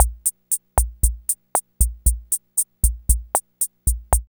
TR55BASSAB0S.wav